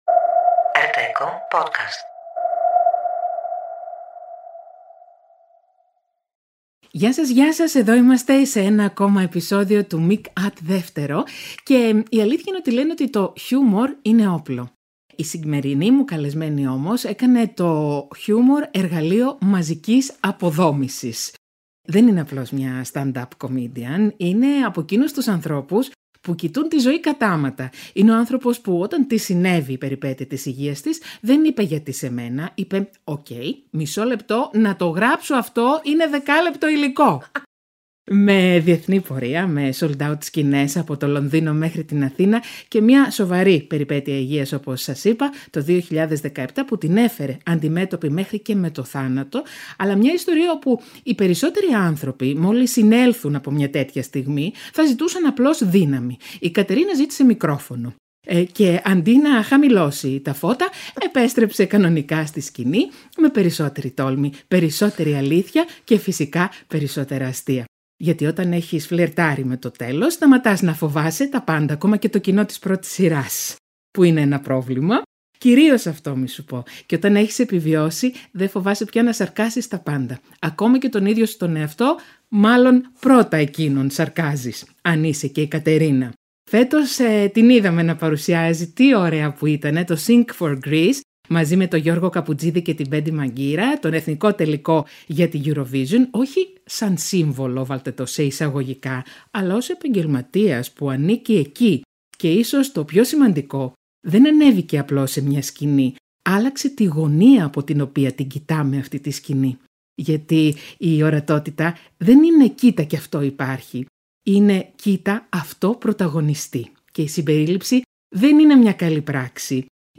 συνομιλεί με την stand-up comedian Κατερίνα Βρανά.